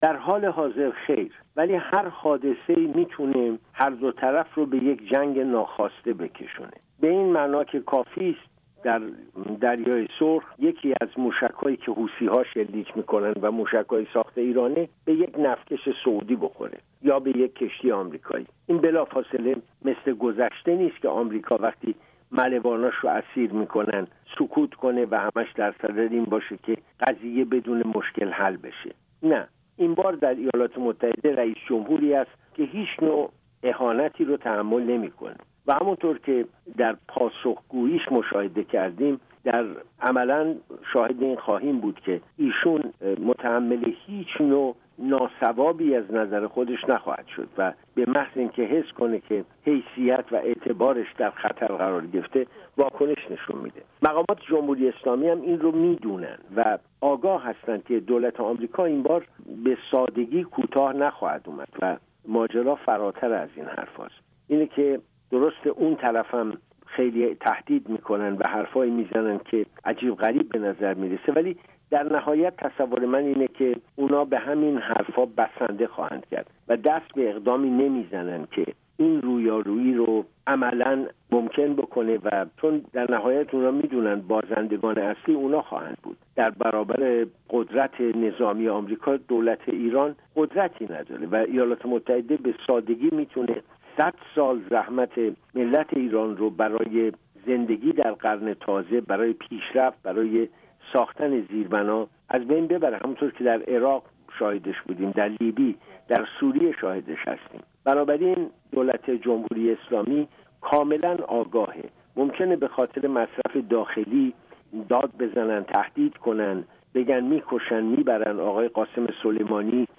مصاحبۀ کامل